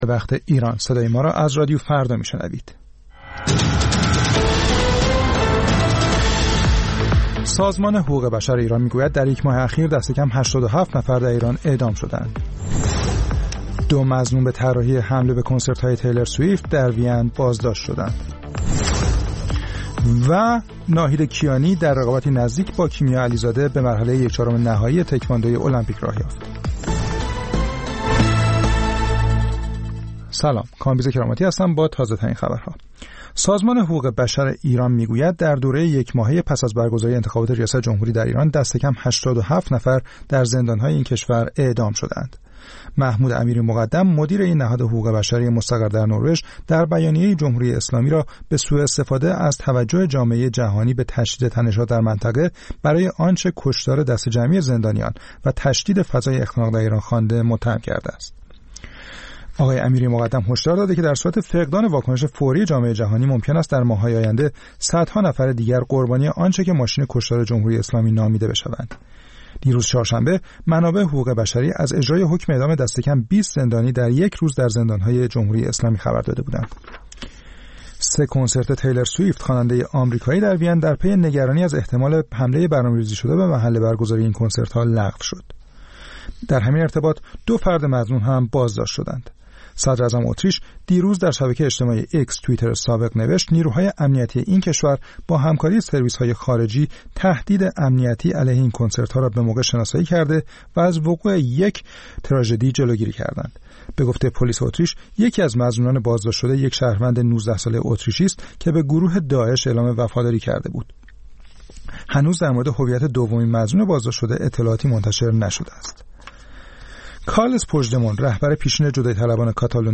سرخط خبرها ۱۷:۰۰